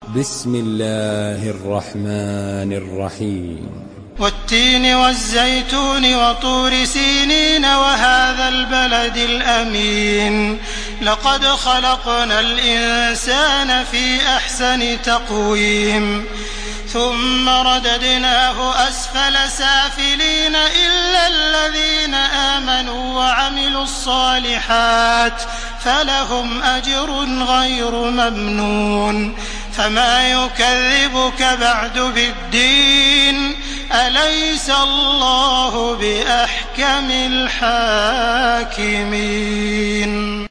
Surah At-Tin MP3 in the Voice of Makkah Taraweeh 1431 in Hafs Narration
Listen and download the full recitation in MP3 format via direct and fast links in multiple qualities to your mobile phone.
Murattal